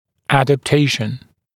[ˌædæp’teɪʃn][ˌэдэп’тэйшн]адаптация